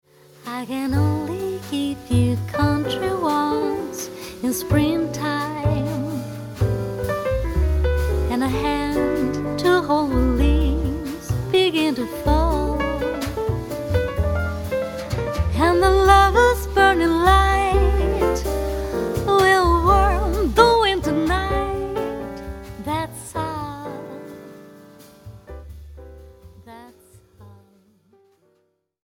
voice
piano
bass
drums